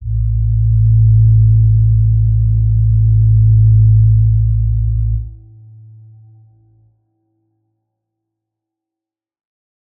G_Crystal-A2-pp.wav